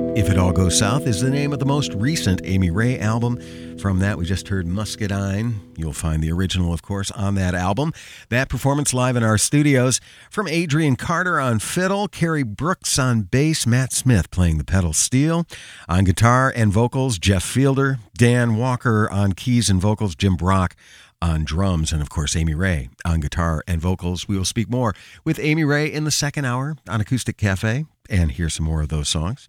(webstream capture)